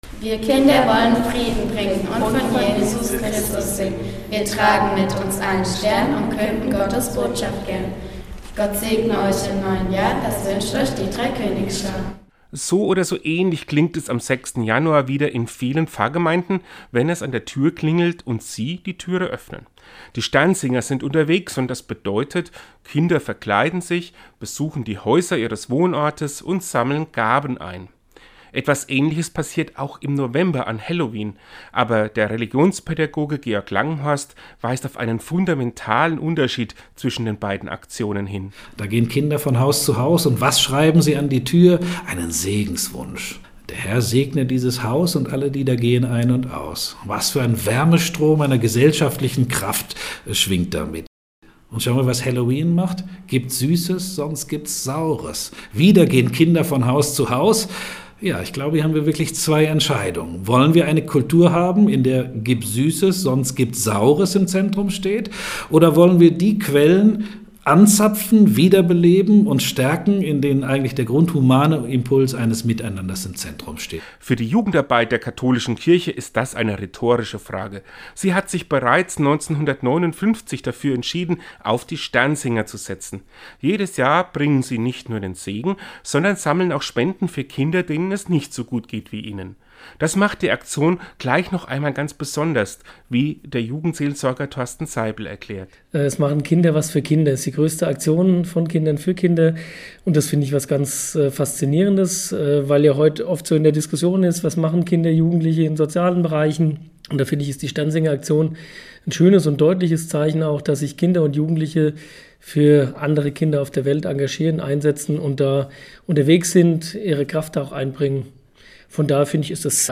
Einen Radiobeitrag zur Sternsingeraussendung finden Sie hier zum Anhören: Quelle: Einzugsgebiet Aschaffenburg Zurück Teilen Downloads 6,5 MB Sternsingeraktion 05.11.2019 | © bv